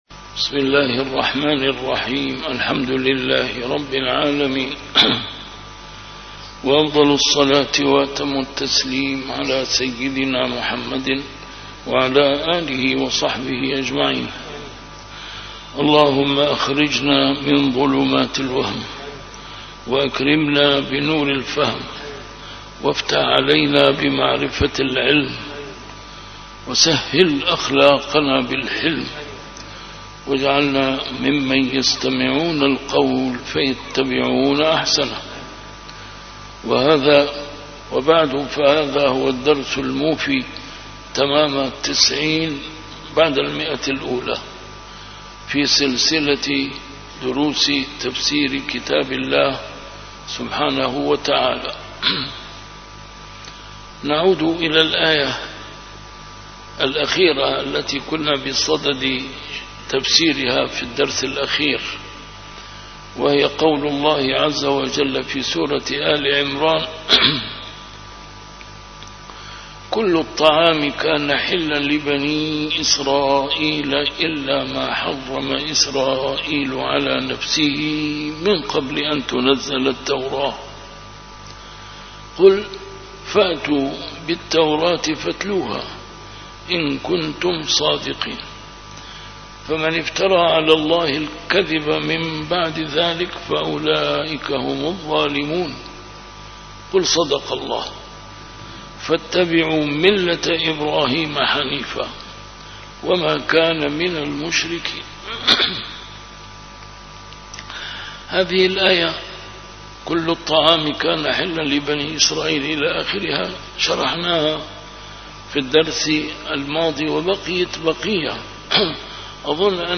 A MARTYR SCHOLAR: IMAM MUHAMMAD SAEED RAMADAN AL-BOUTI - الدروس العلمية - تفسير القرآن الكريم - تفسير القرآن الكريم / الدرس التسعون بعد المائة: سورة آل عمران: الآية 93 - 97